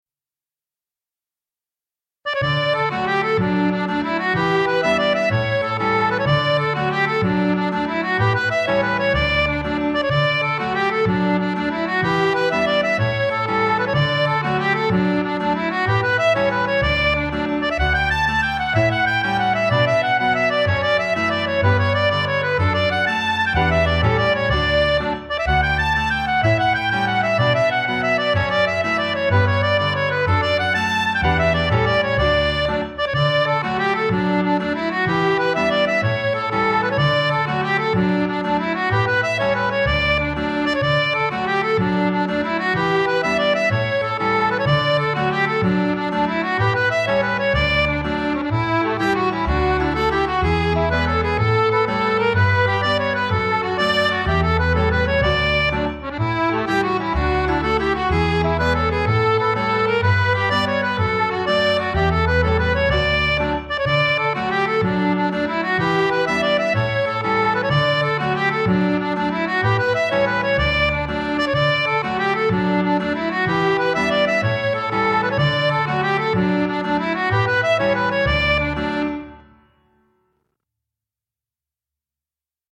Violin
A collection of original tunes in the traditional style for
fiddle and accordian.